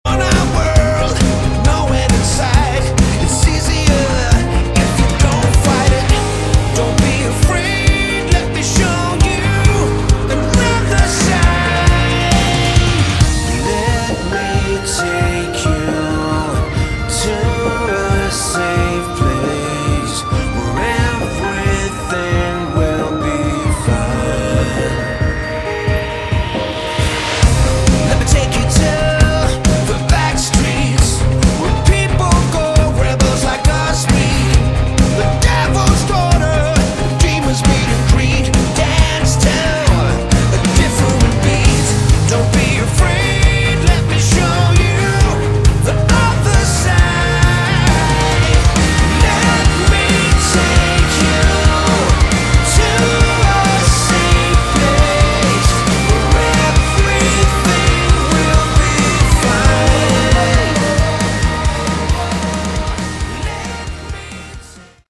Category: Melodic Rock
bass, vocals
drums
keyboards
guitars